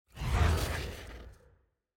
sfx-jfe-ui-roomselect-appear.ogg